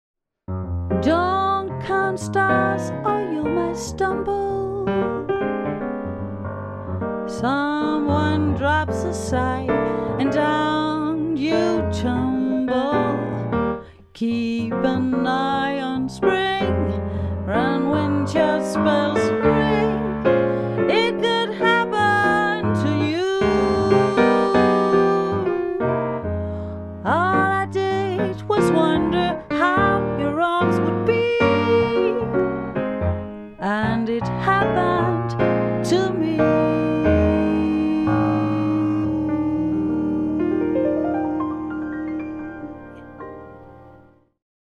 klaver/sang-duo
swingende vokaljazz
• Jazzband